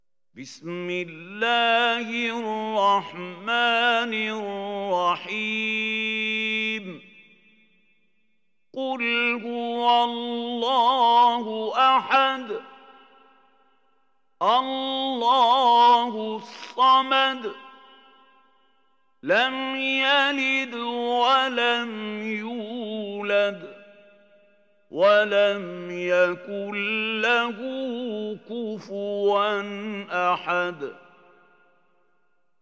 دانلود سوره الإخلاص mp3 محمود خليل الحصري (روایت حفص)